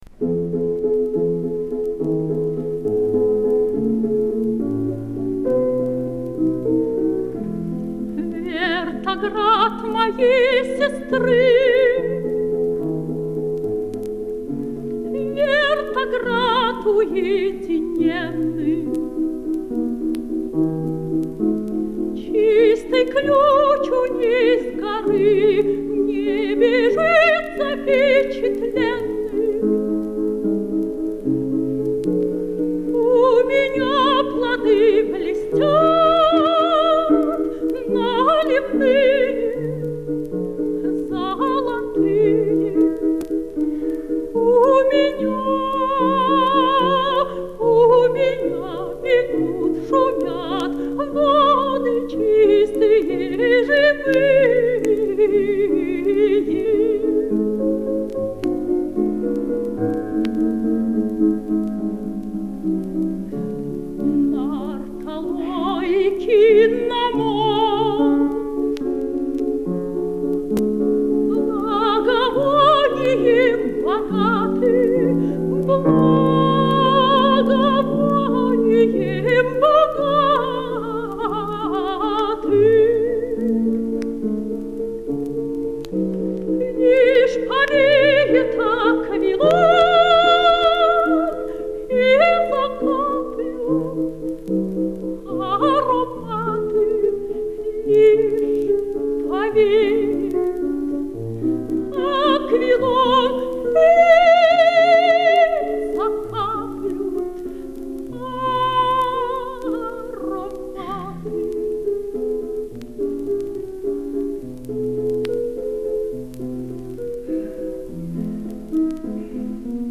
ф-но). Москва, 1953 (live).